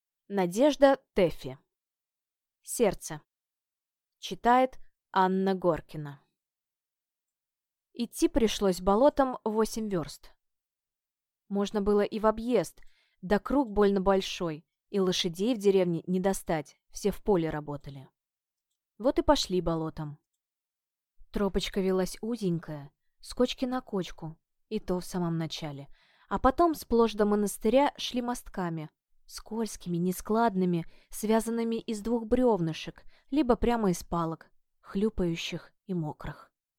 Аудиокнига Сердце | Библиотека аудиокниг